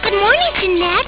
Touch the pic or name to hear Azusa say it.